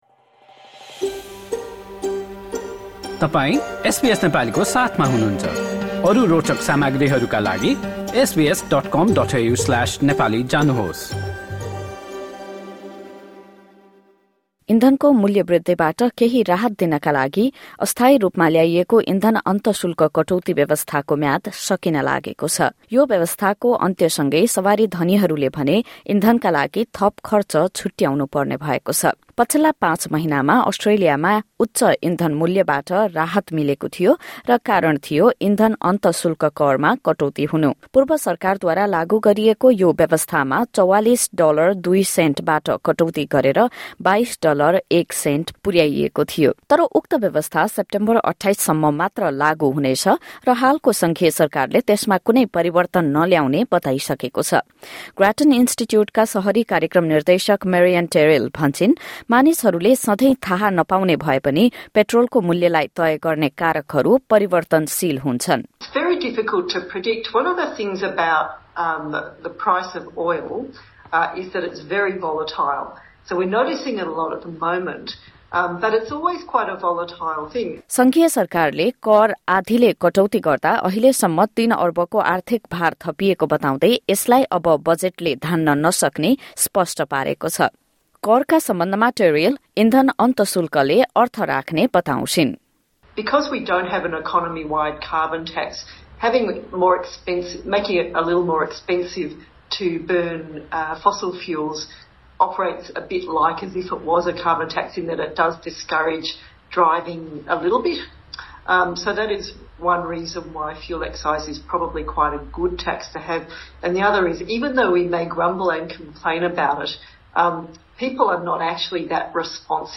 LISTEN TO पुरा रिपोर्ट सुन्नुहोस् 04:44 हाम्रा थप अडियो प्रस्तुतिहरू पोडकास्टका रूपमा उपलब्ध छन्।